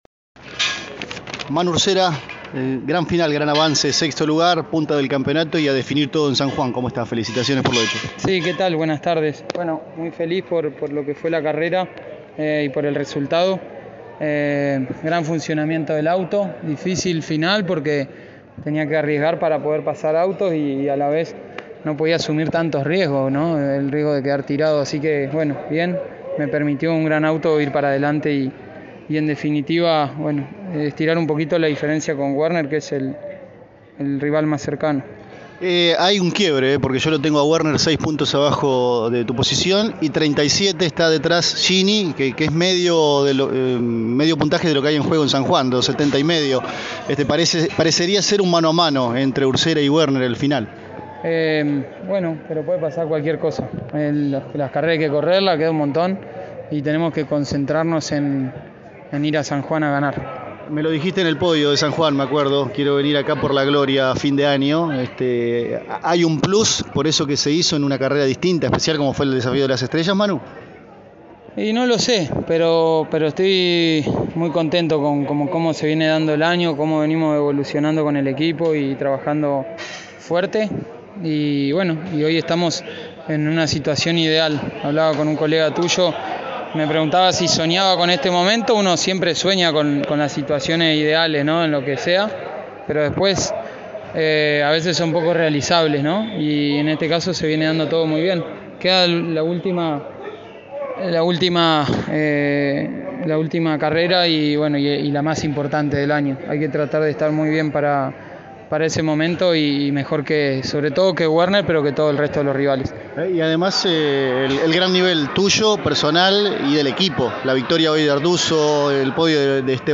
Escuchamos la palabra de José Manuel Urcera: